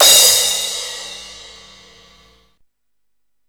CRASH10   -R.wav